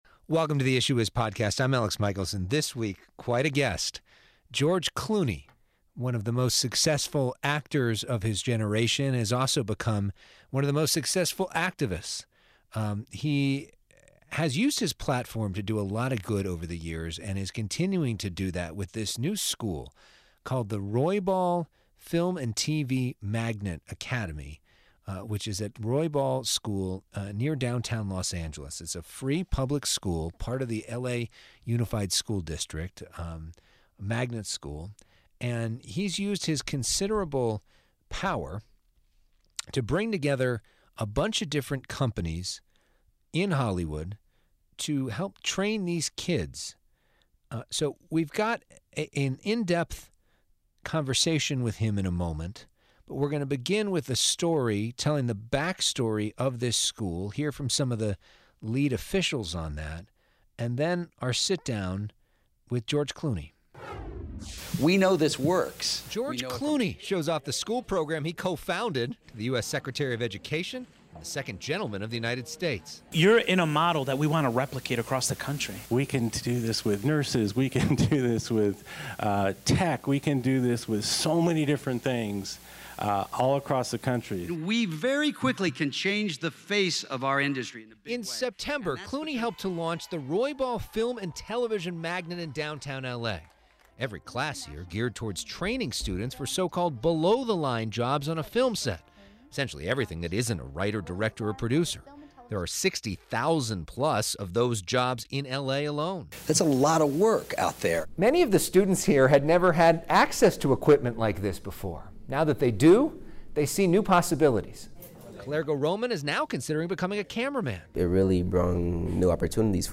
This week, an exclusive conversation with George Clooney.